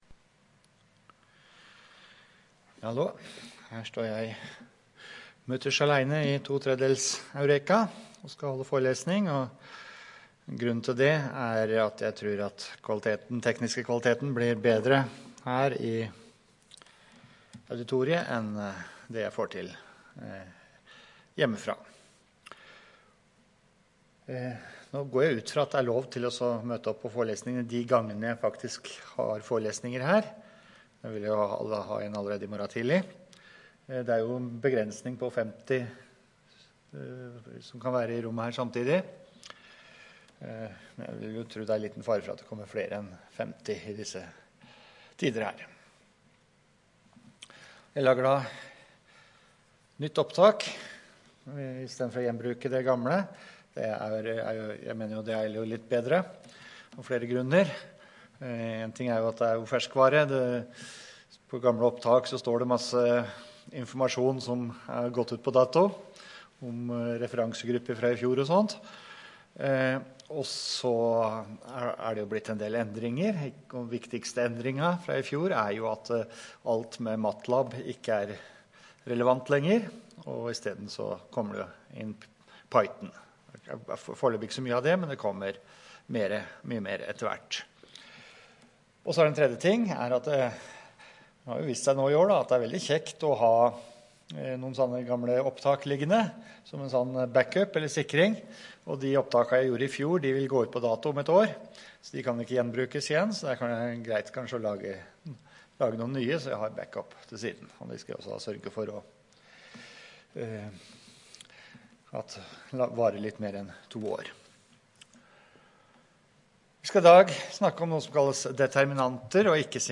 Rom: Store Eureka, 2/3 Eureka